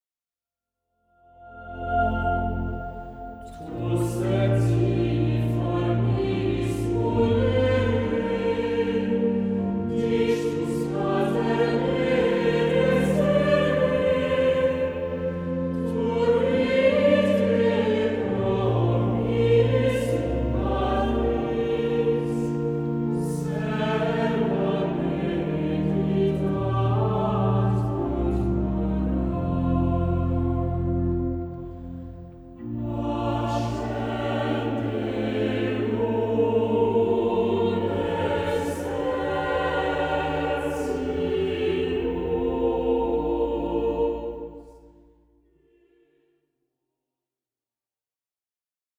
Harmonisations originales de chants d'assemblée